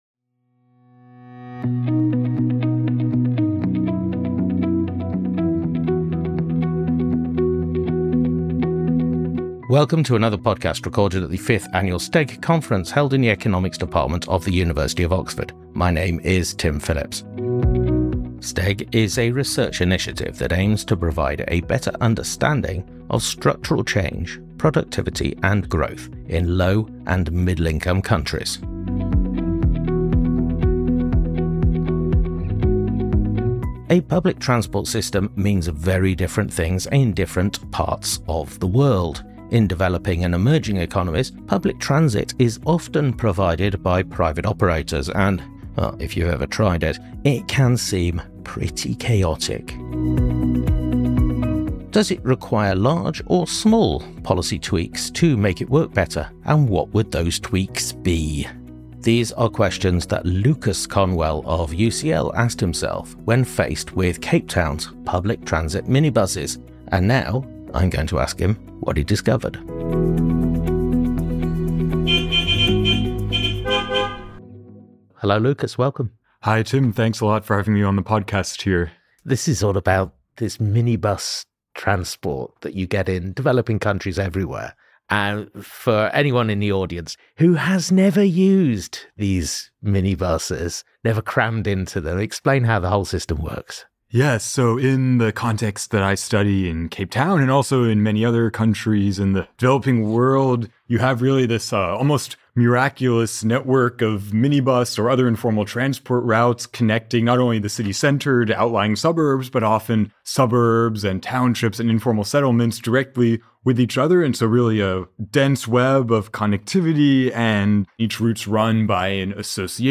Recorded at the 2025 annual conference of the Structural Transformation and Economic Growth Programme, held at the University of Oxford.